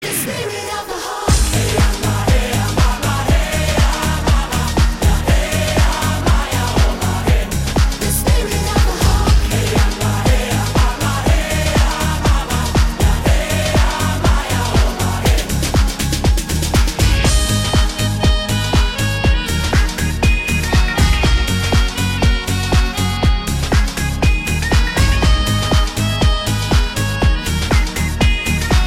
B Besetzung: Blasorchester PDF